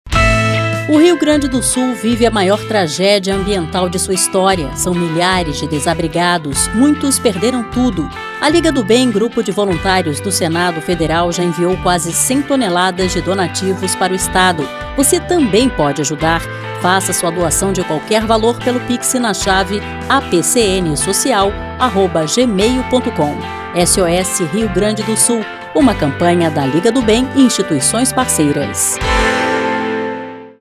Spot sobre doações para o RS está disponível para veiculação gratuita